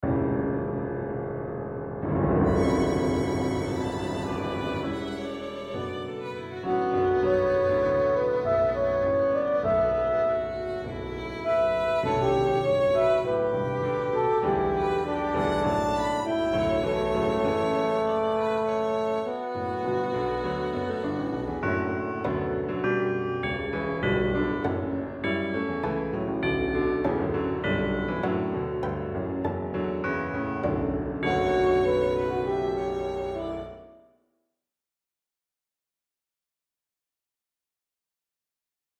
Voicing: Chamber Quartet